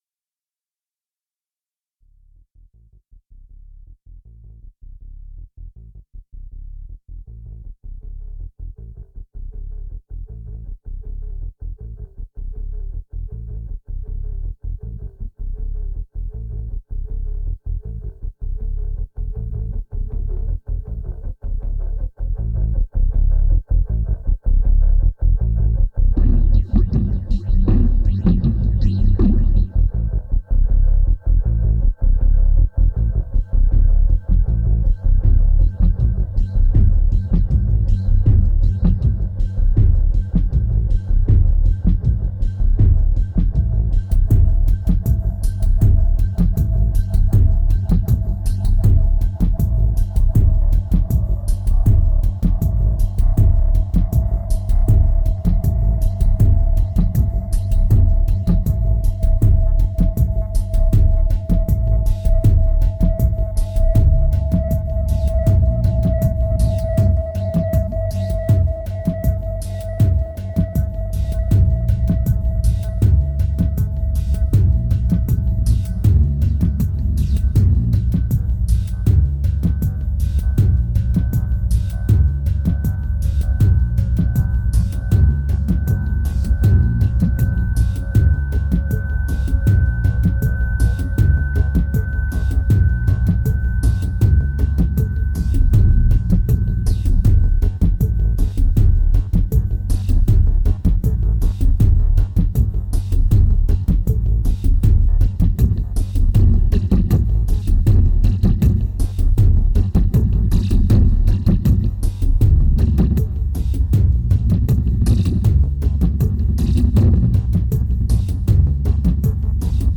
2213📈 - 10%🤔 - 80BPM🔊 - 2010-09-22📅 - -130🌟